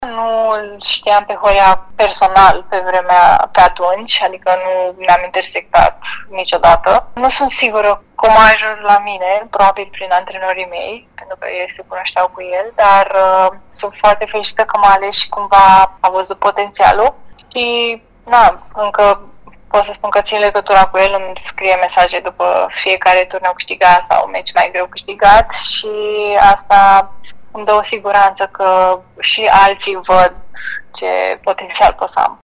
Într-un interviu pentru Radio Timișoara